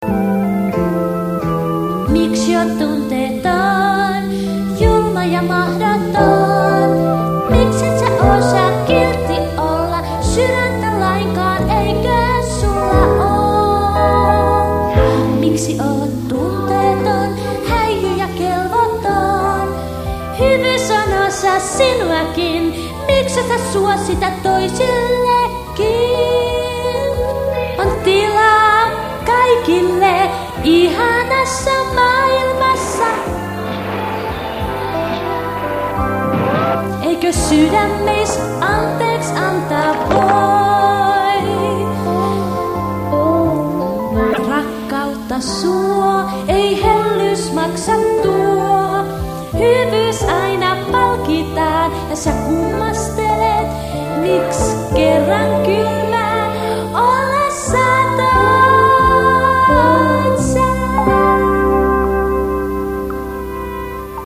lauloin teille pienen laulun :3